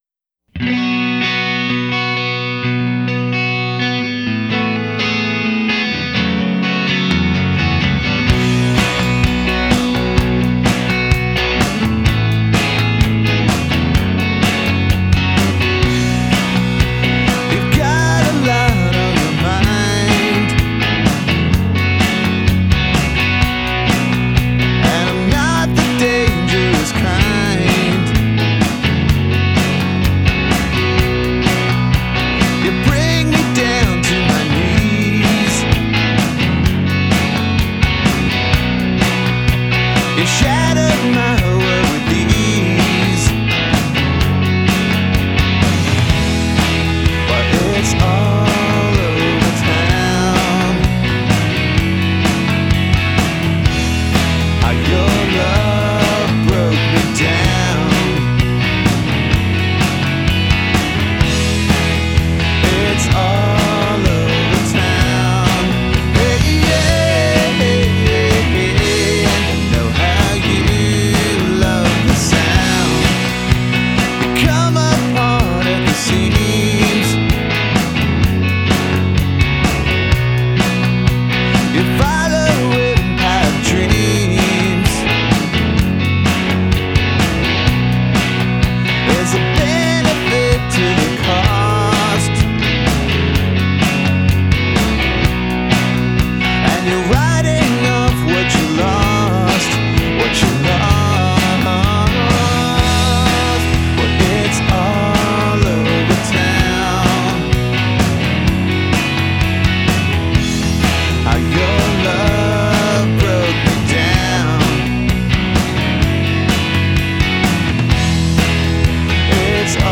Tempo 127 BPM
Key B